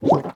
Minecraft Version Minecraft Version 1.21.5 Latest Release | Latest Snapshot 1.21.5 / assets / minecraft / sounds / entity / witch / drink2.ogg Compare With Compare With Latest Release | Latest Snapshot
drink2.ogg